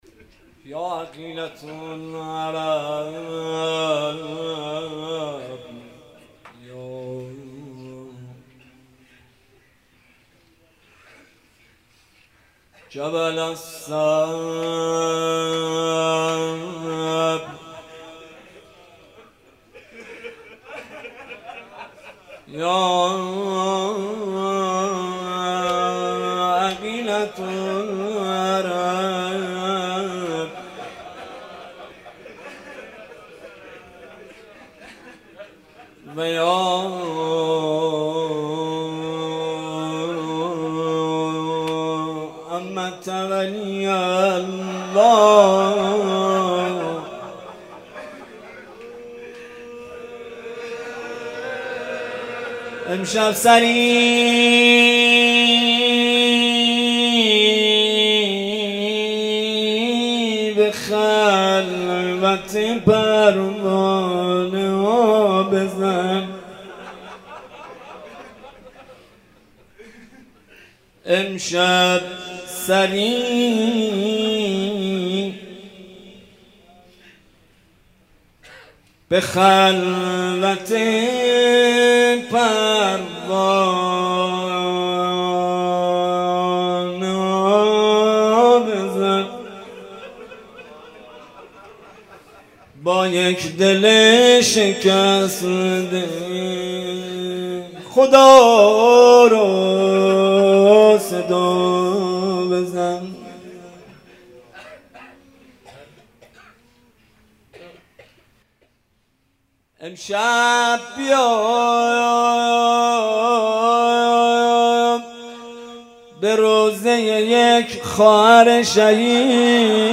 روضه حضرت زینب